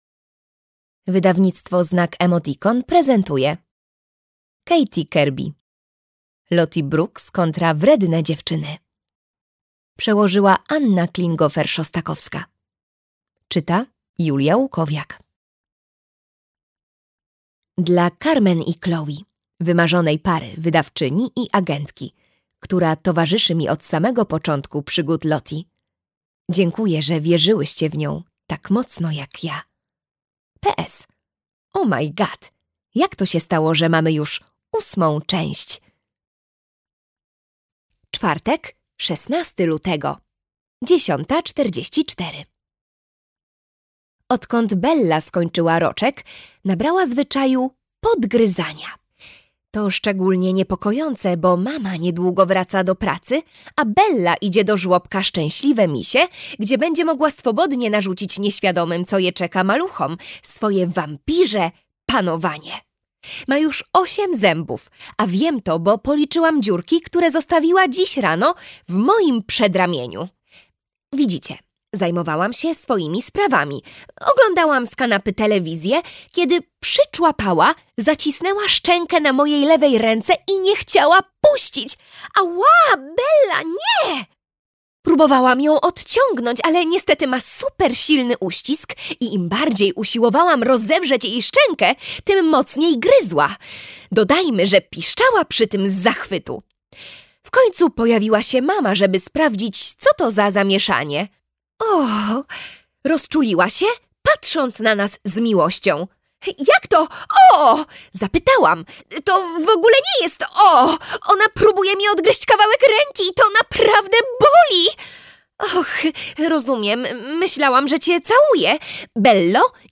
Lottie Brooks kontra wredne dziewczyny - Kirby Katie - audiobook